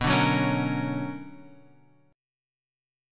B Minor Major 9th